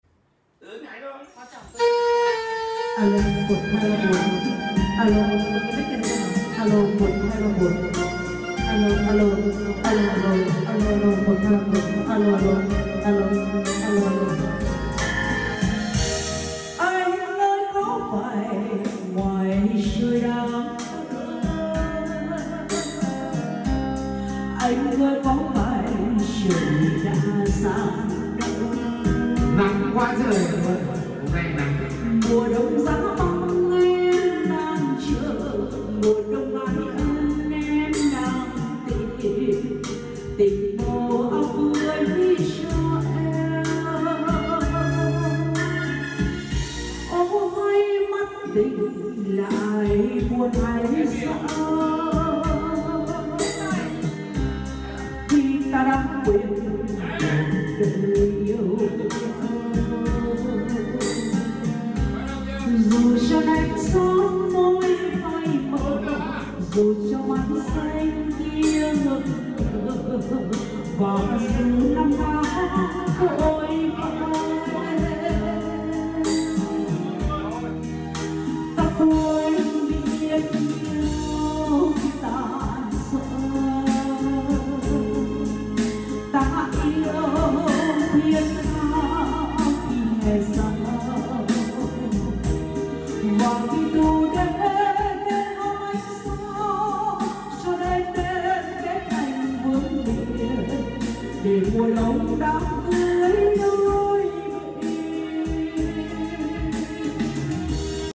Combo nghe nhạc hát karaoke sound effects free download
Combo nghe nhạc hát karaoke trên đôi loa cây H911 bas đôi 25